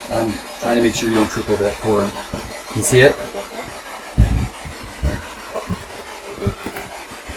I was heading up the stairs doing an SB11 spirit box session, and commented to the person filimg me that I didn't want them to trip on a cable that I could see dangling down from the top railing area. The entity has a mocking comment about someone possibly tripping. Dark voice mocks us on stairs "I'd HATE to see THAT!" show/hide spoiler Back to Villisca Axe Murder House Evidence Page